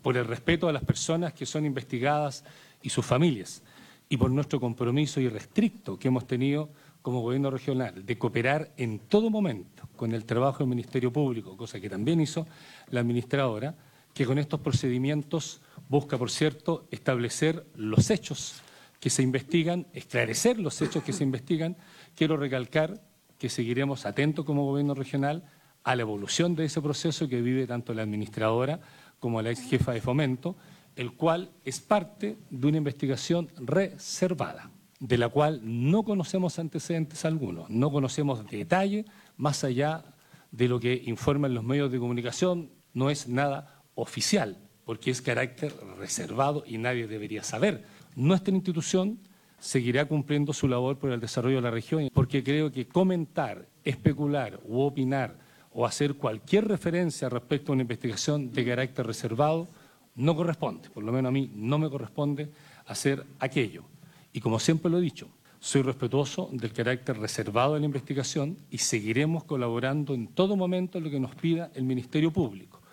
Al inicio de la Sesión Plenaria número 22 del Consejo Regional de Los Lagos, el Gobernador Patricio Vallespin se refirió a la detención de ambas personas, señalando que respetaran el irrestricto carácter reservado de la investigación que lleva adelante el Ministerio Público.